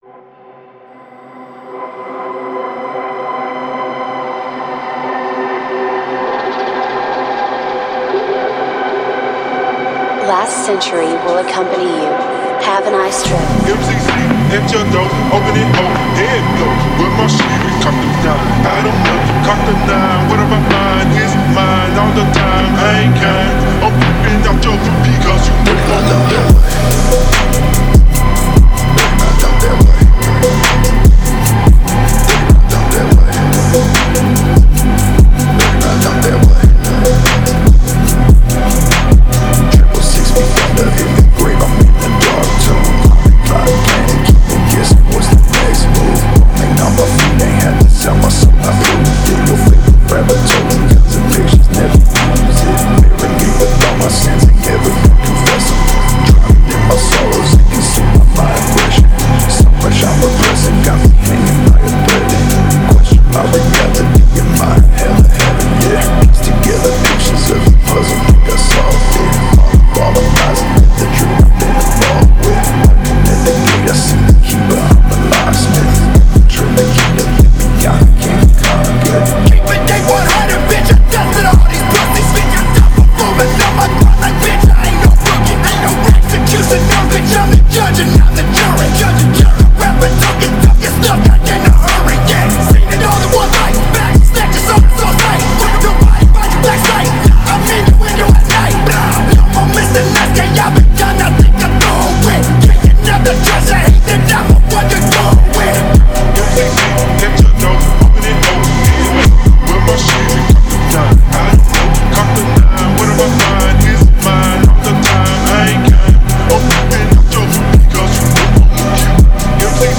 Фонк музыка